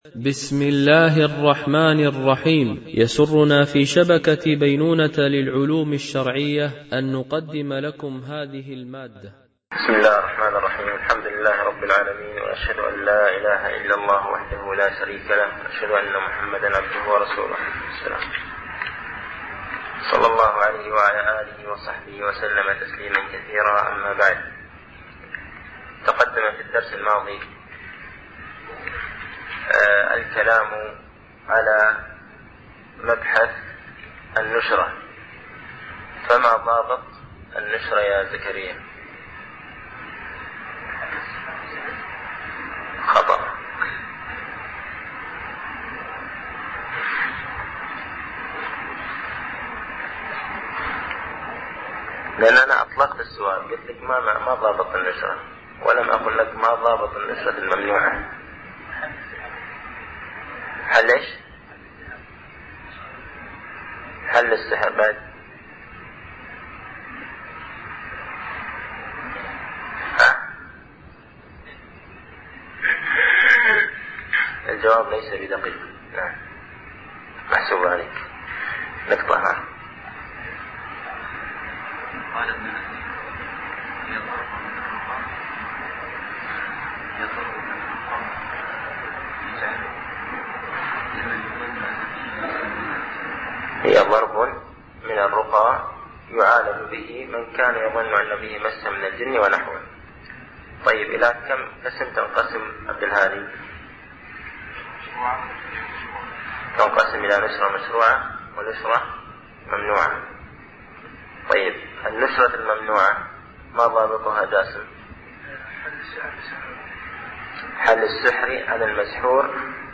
) الألبوم: شبكة بينونة للعلوم الشرعية التتبع: 97 المدة: 61:55 دقائق (14.21 م.بايت) التنسيق: MP3 Mono 22kHz 32Kbps (CBR)